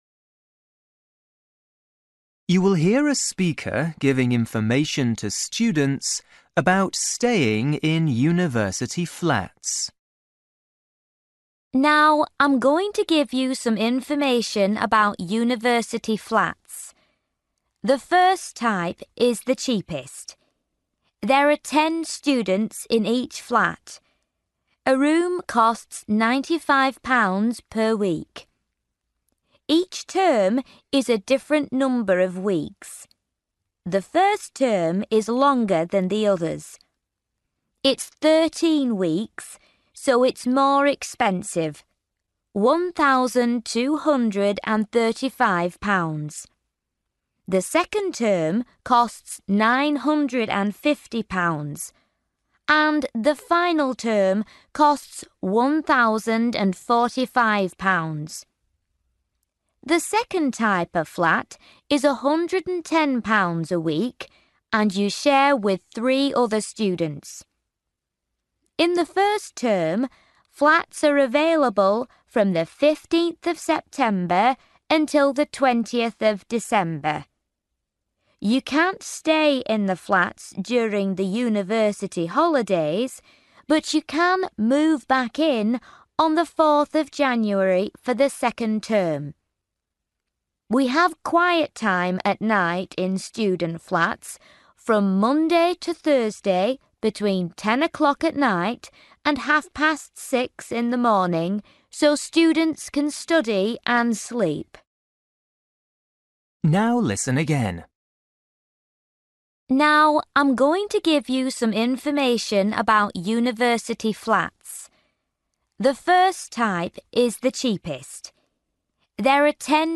You will hear a speaker giving information to students about staying in university flats.